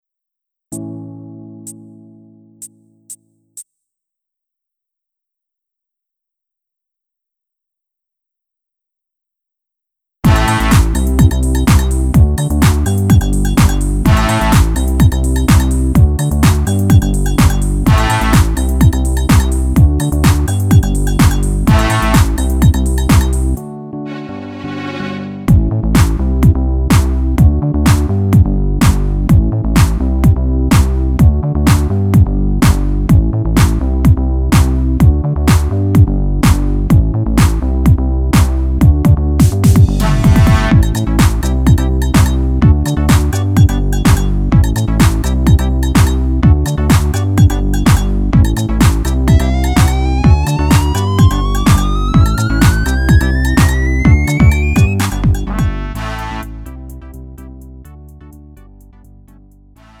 음정 원키 3:23
장르 가요 구분